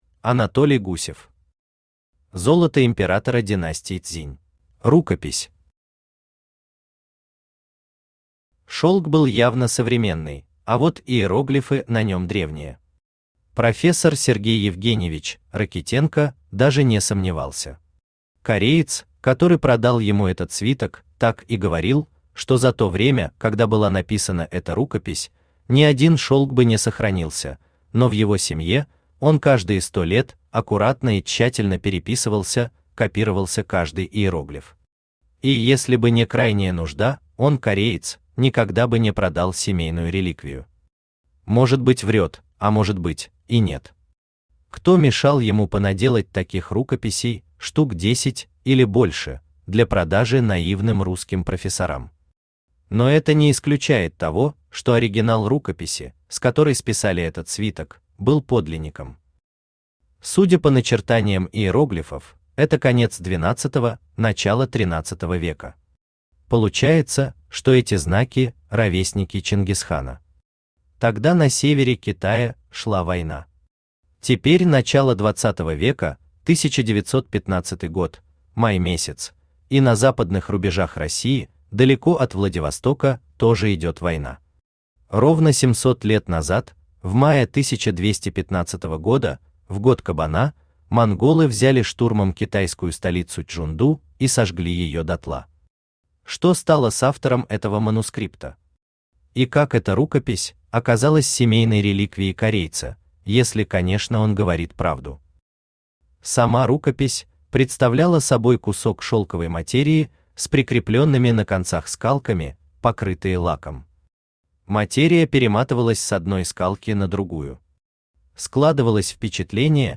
Аудиокнига Золото императора династии Цзинь | Библиотека аудиокниг
Aудиокнига Золото императора династии Цзинь Автор Анатолий Алексеевич Гусев Читает аудиокнигу Авточтец ЛитРес.